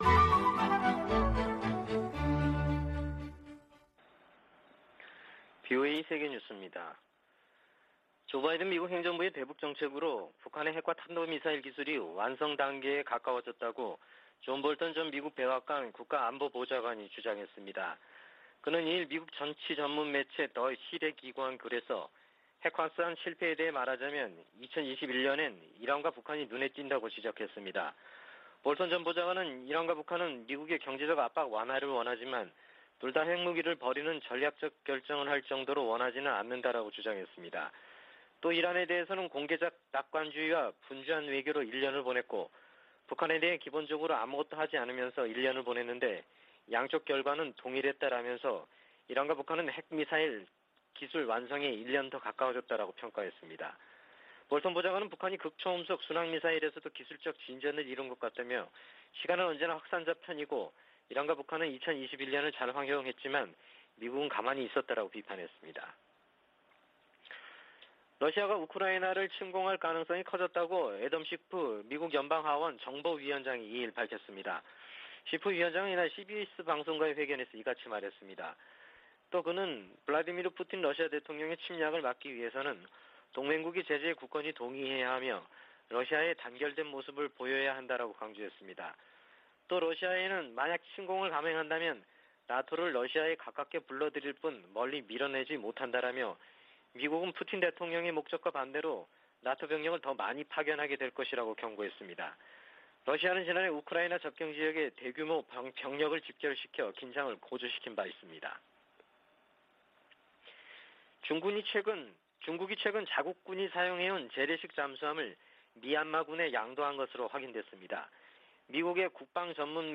VOA 한국어 아침 뉴스 프로그램 '워싱턴 뉴스 광장' 2021년 1월 4일 방송입니다. 문재인 한국 대통령이 한반도 평화 제도화 노력을 멈추지 않겠다고 신년사에서 밝혔습니다. 조 바이든 미국 대통령이 역대 최대 규모의 국방예산을 담은 2022국방수권법안에 서명했습니다. 탈북민들은 새해를 맞아 미국 등 국제사회가 북한 인권 문제에 더 초점을 맞출 것을 희망했습니다.